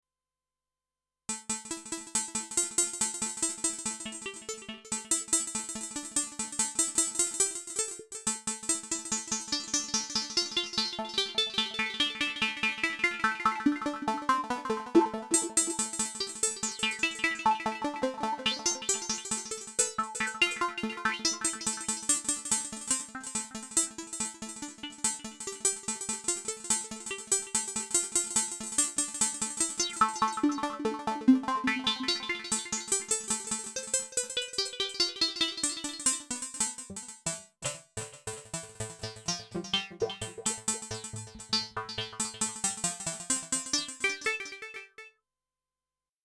Это пресет из JV-1080 ну и XV модулей соответственно.
Примерчик со своего модуля тоже приложил) Вложения JVXV - Velo Tekno 2.mp3 JVXV - Velo Tekno 2.mp3 1,8 MB · Просмотры: 2.020